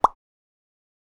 pop.wav